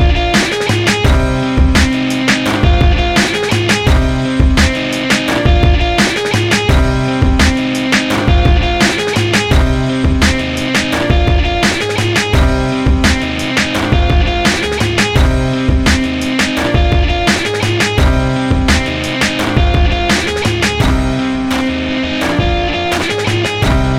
no Backing Vocals R'n'B / Hip Hop 4:35 Buy £1.50